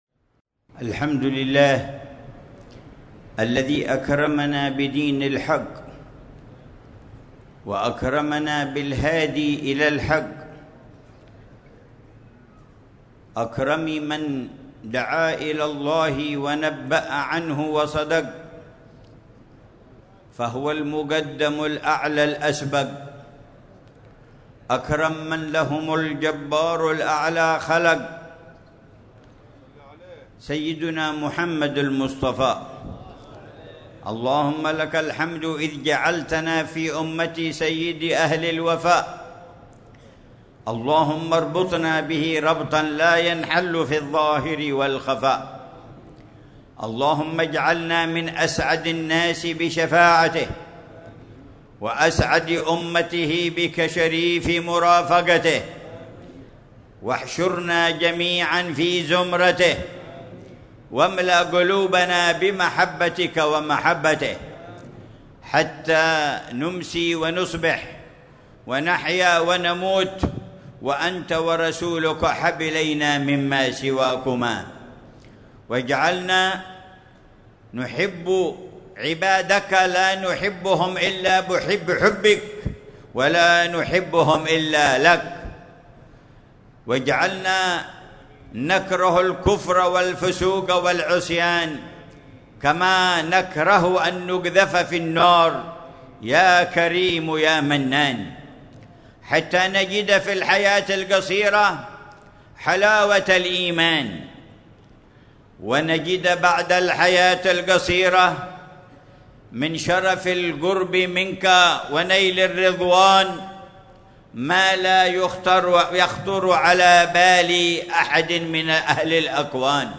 محاضرة العلامة الحبيب عمر بن محمد بن حفيظ في جامع الروضة، بمدينة المكلا، ساحل حضرموت، ليلة الثلاثاء 15 ربيع الثاني 1447هـ، بعنوان: الترجمة عن الإيمان والمحبة بالمشاعر والأذواق والعمل